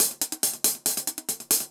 UHH_AcoustiHatC_140-05.wav